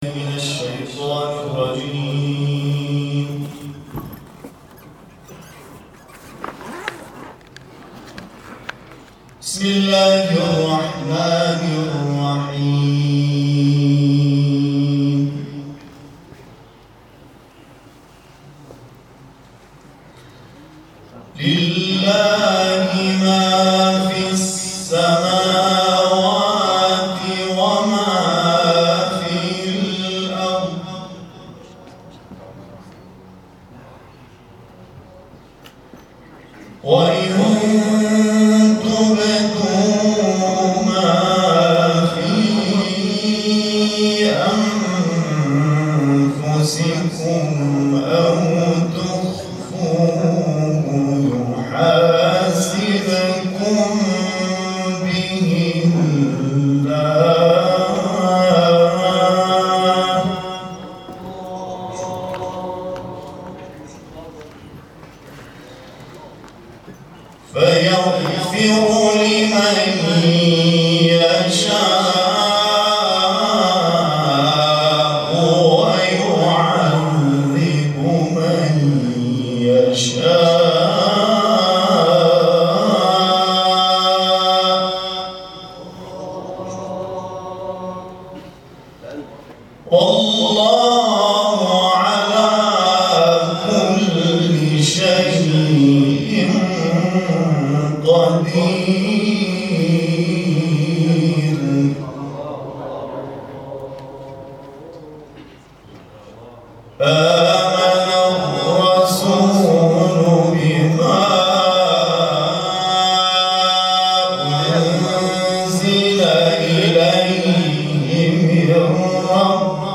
قاری بین‌المللی کشورمان در جدیدترین تلاوت خود آیات 284 تا 286 از سوره مبارکه بقره را تلاوت کرد.
وی طی این مراسم آیات 284 تا 286 سوره مبارکه بقره را تلاوت کرد که مورد توجه دانشجویان دانشگاههای افسری آجا قرار گرفت.
قاری بین‌المللی